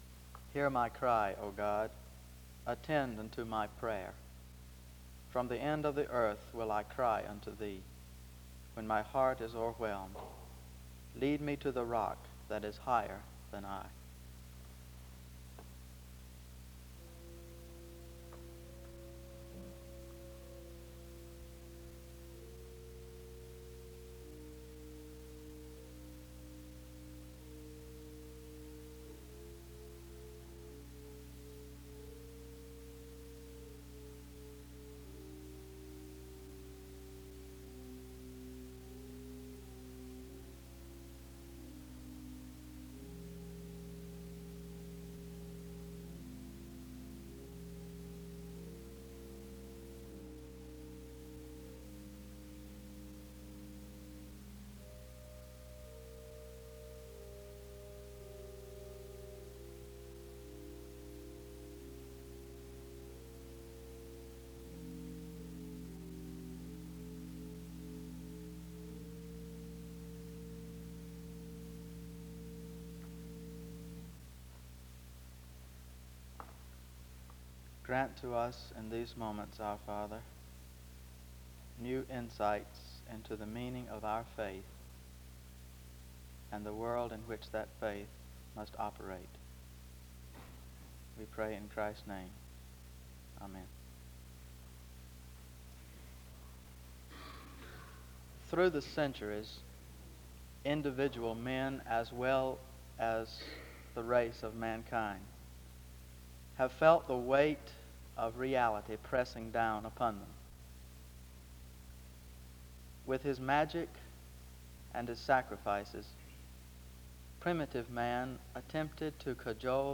This service was organized by the Student Coordinating Council.